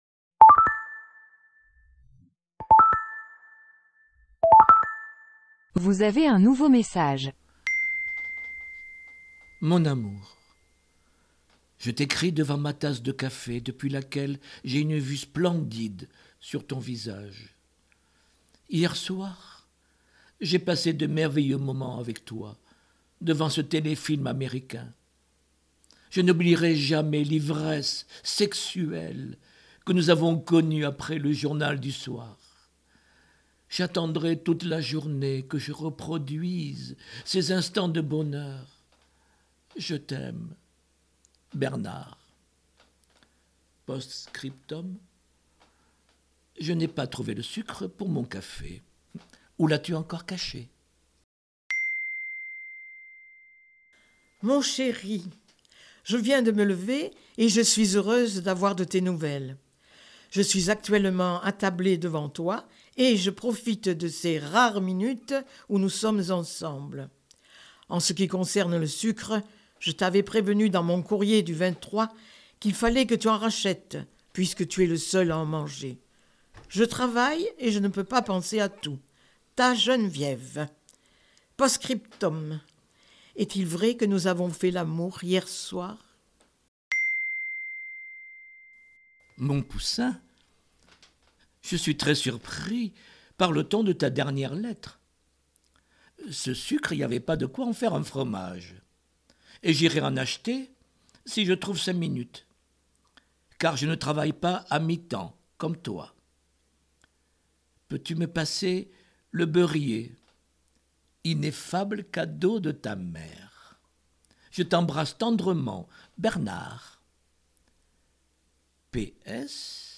lu par